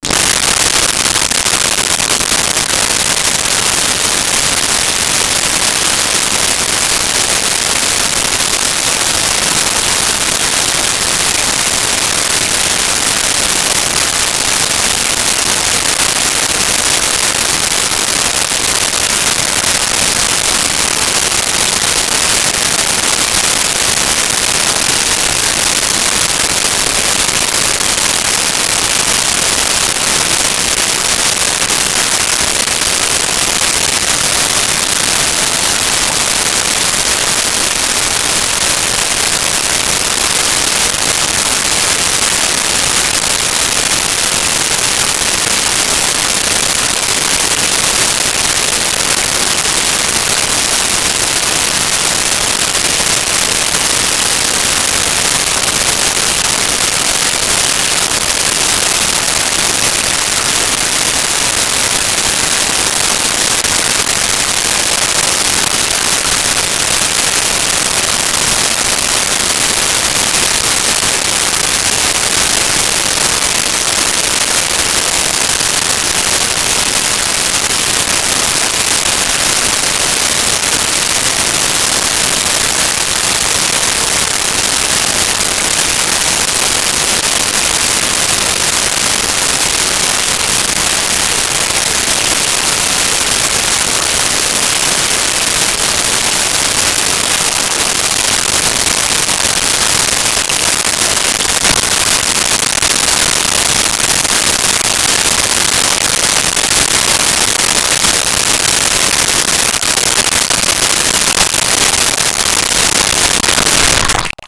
Toilet Urinating Long
Toilet Urinating Long.wav